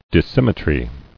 [dis·sym·me·try]